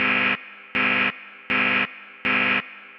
GoAlarm.wav